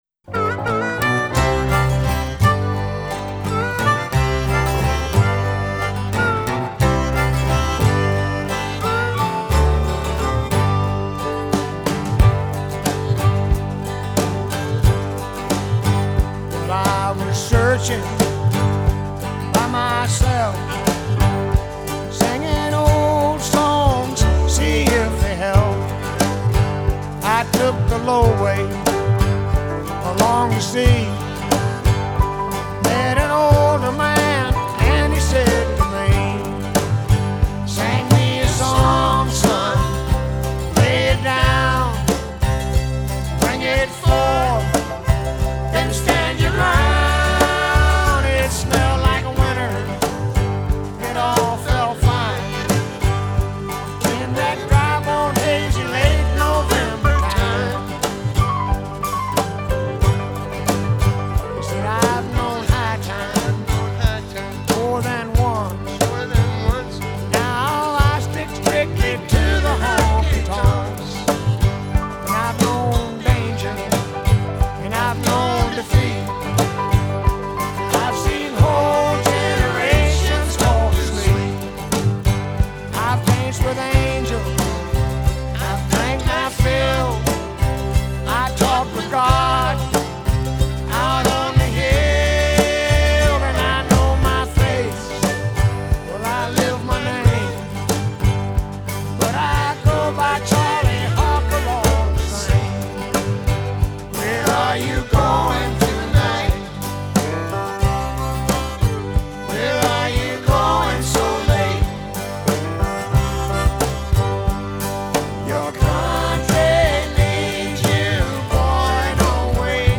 Жанр: Country Rock